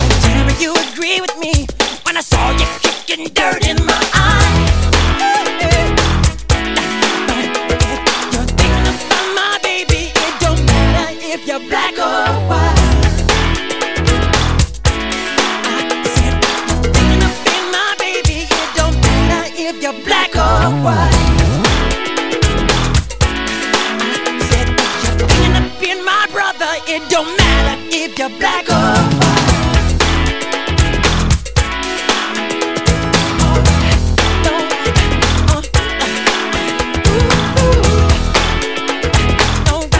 танцевальные , зарубежные , 90-е , поп
фанк